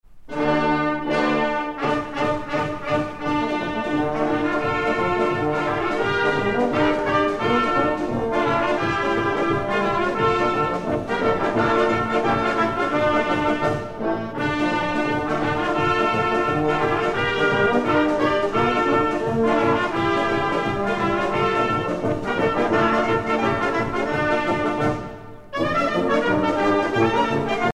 danse : horo (Bulgarie)